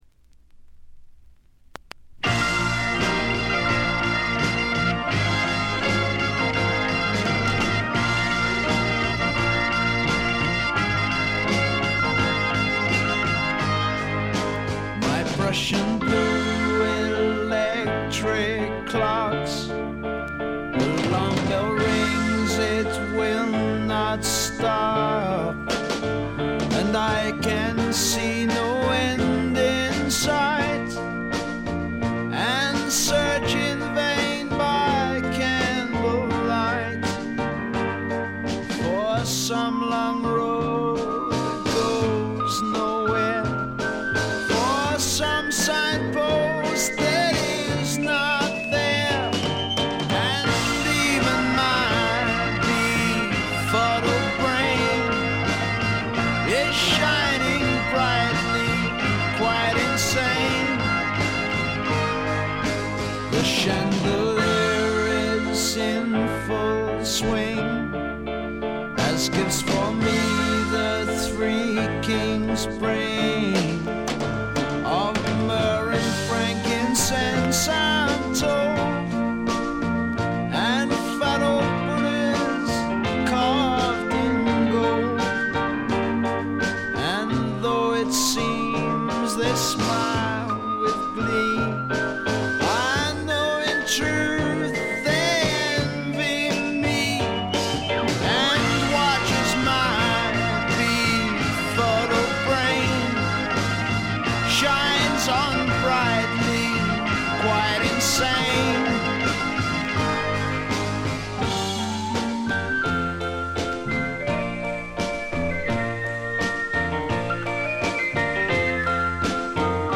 見た目よりやや劣る感じで、静音部でバックグラウンドノイズ、軽微なチリプチ。
試聴曲は現品からの取り込み音源です。